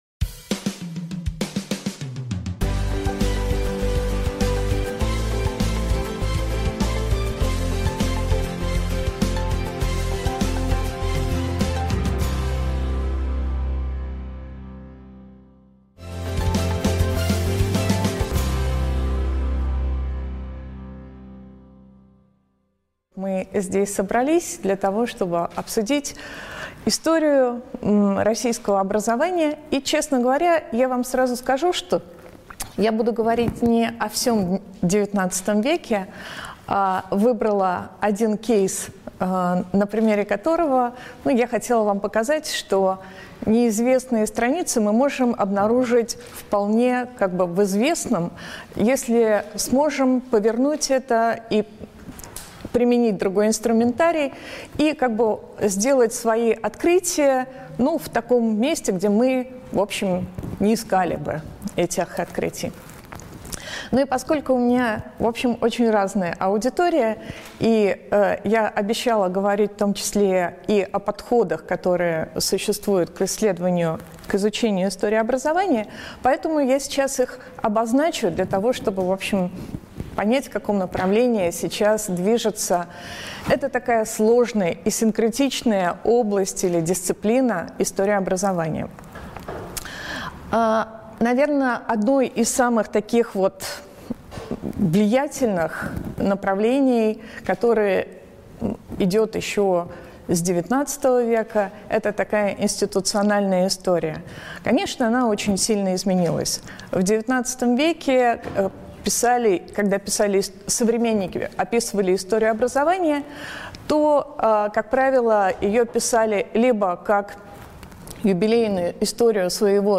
Аудиокнига Неизвестная история российского образования XIX века | Библиотека аудиокниг